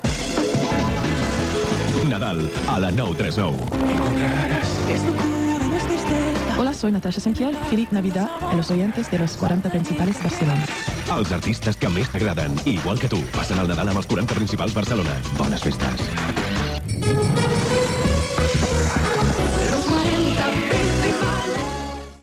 Indicatiu nadalenc de l'emissora
FM